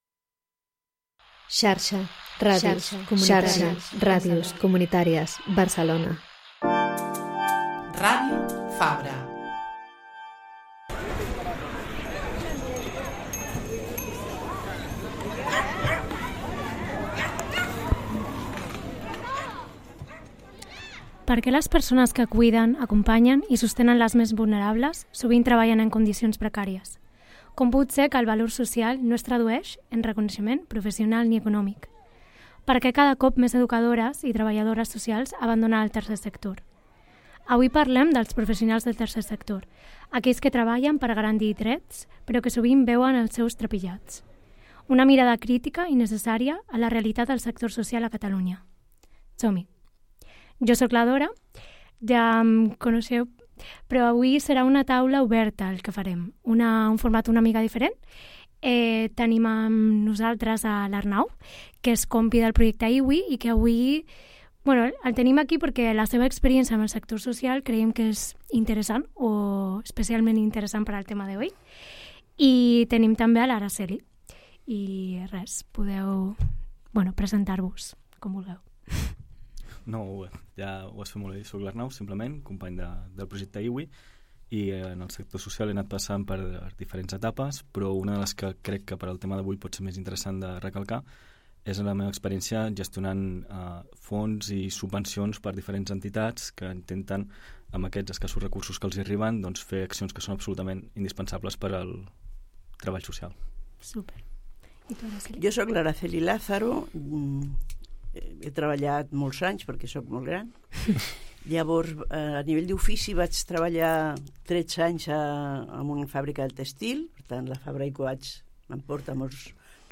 Reflexionem sobre el finançament i les condicions laborals, i també sobre el prestigi social i els estigmes que encara arrosseguen professions com Educació Social o Magisteri. Ens acompanyen diverses veus vinculades al sector, que comparteixen les seves experiències i històries en primera persona.